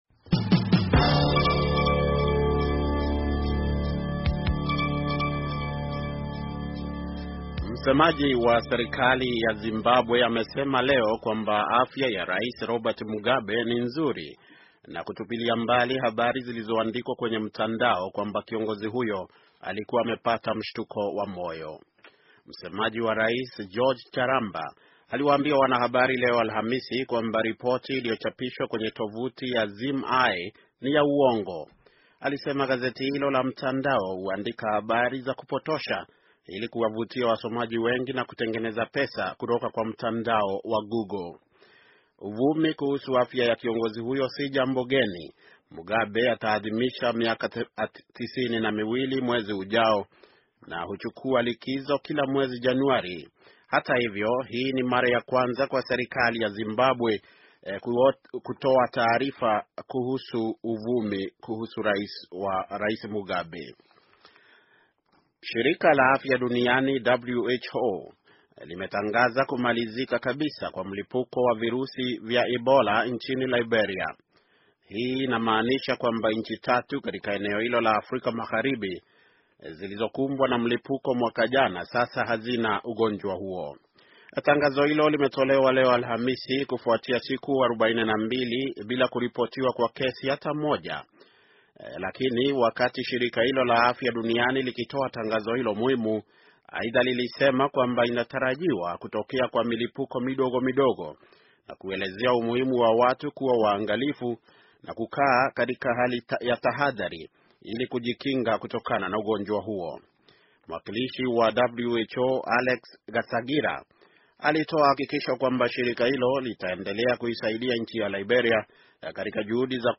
Taarifa ya habari - 6:31